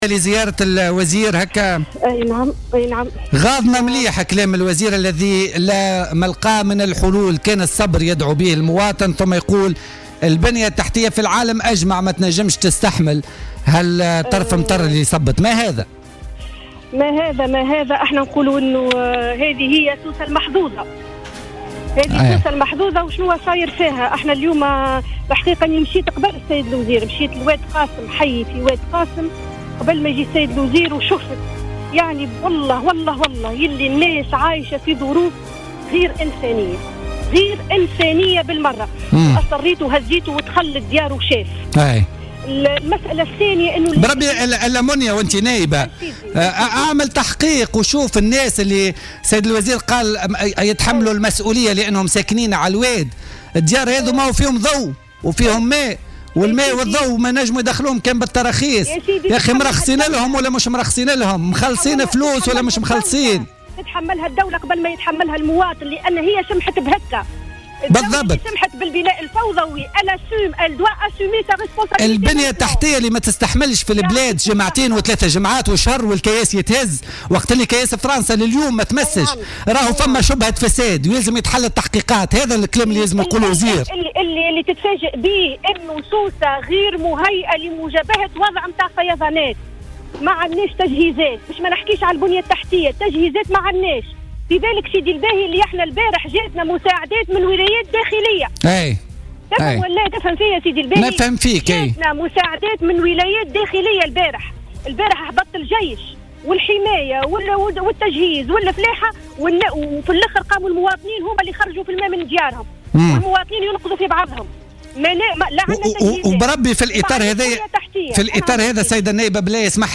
أكدت النائبة عن حركة النهضة منية بن ابرهيم في مداخلة لها في الجوهرة "اف ام" أن واكبت زيارة التجهيز للإطلاع على مخلفات الأمطار التي شهدتها ولاية سوسة أمس الخميس ورافقت الوزير لزيارة بعض المناطق على غرار وادي قاسم التي يعيش سكانها ظروف لا انسانية بالمرة على حد قولها.